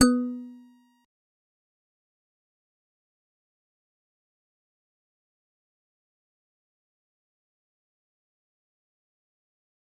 G_Musicbox-B3-pp.wav